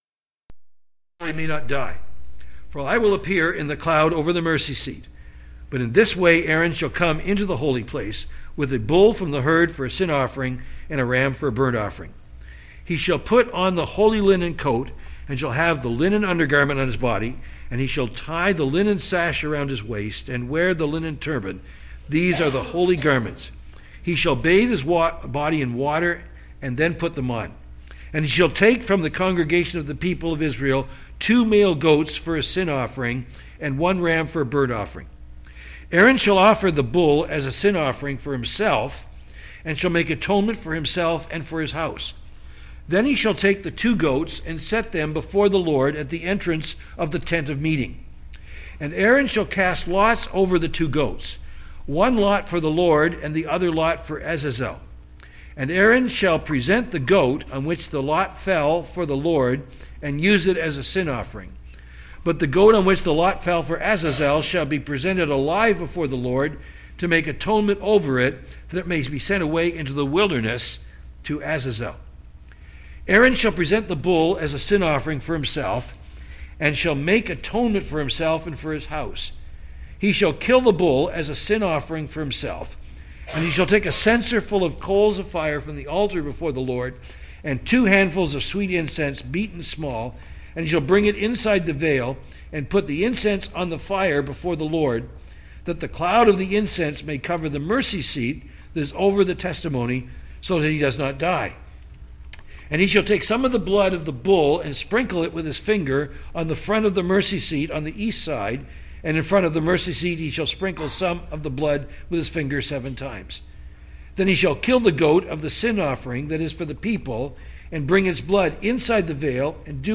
Sermons | Bethel Bible Church